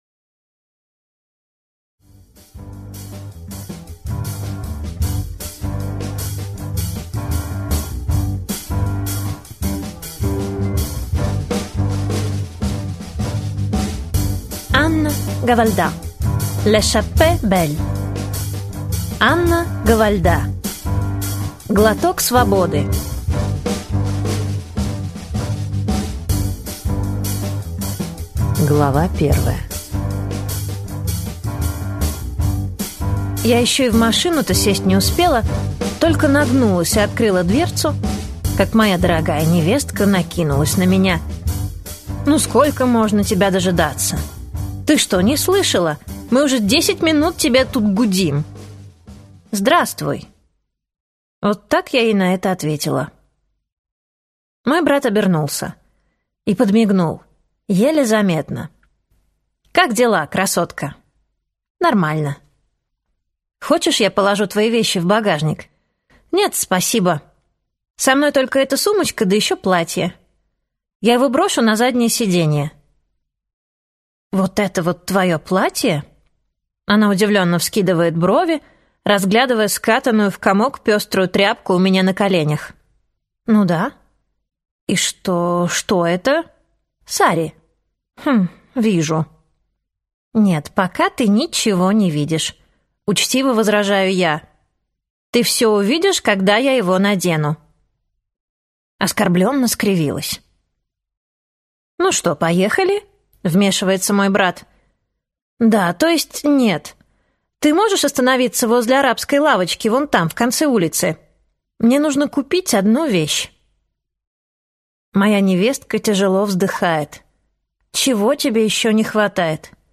Аудиокнига Глоток свободы - купить, скачать и слушать онлайн | КнигоПоиск